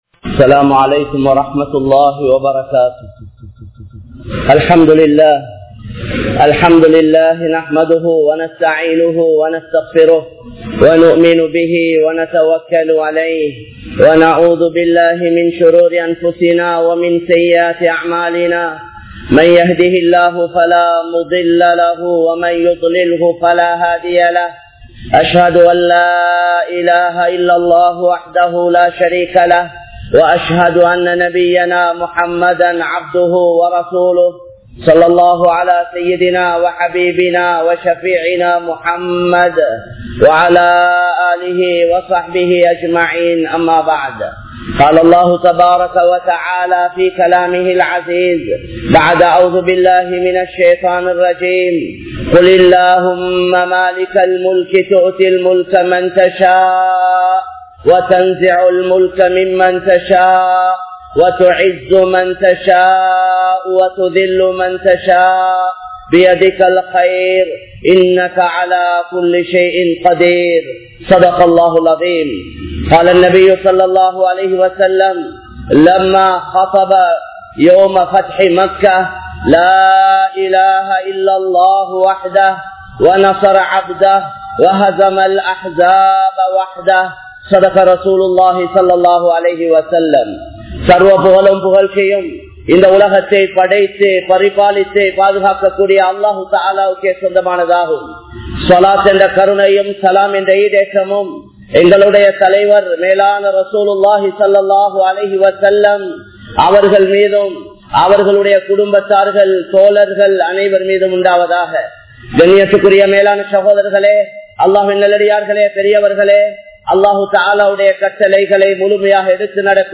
Mannukku Iraiyaahum Manitha Udampu (மண்ணுக்கு இரையாகும் மனித உடம்பு) | Audio Bayans | All Ceylon Muslim Youth Community | Addalaichenai
Dehiwela, Muhideen (Markaz) Jumua Masjith